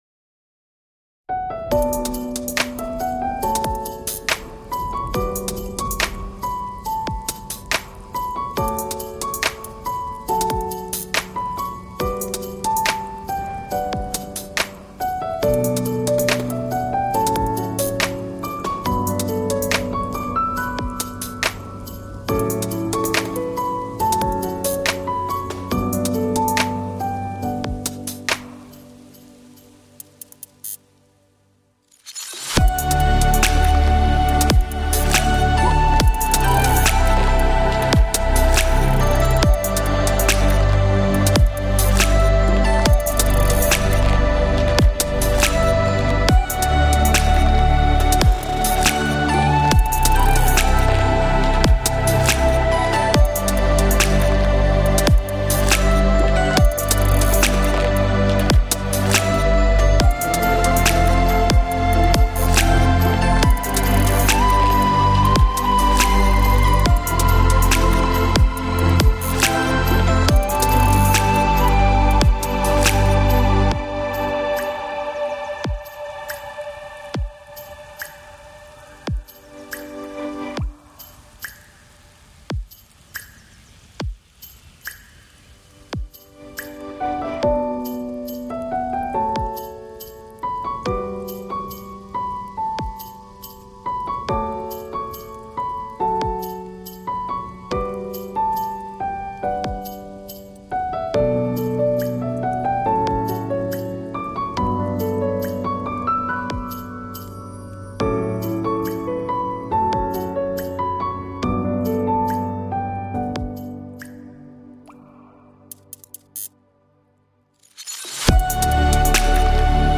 DJ/Remixer
Christmas spin